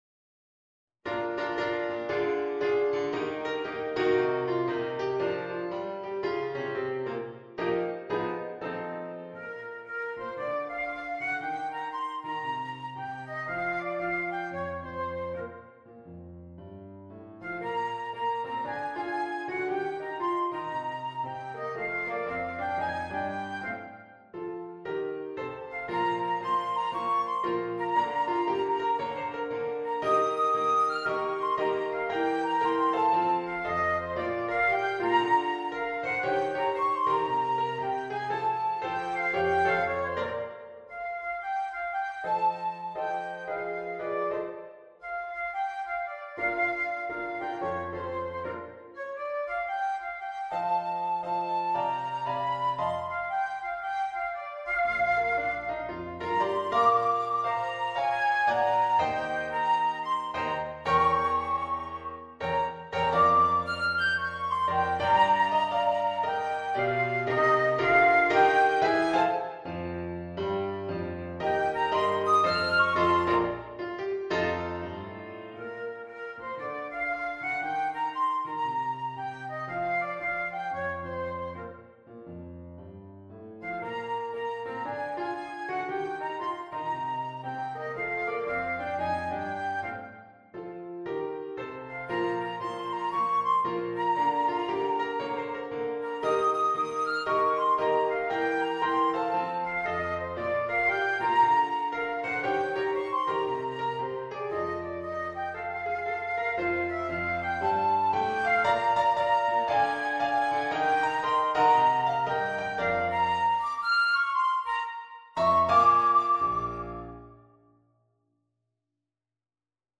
Jazz and Blues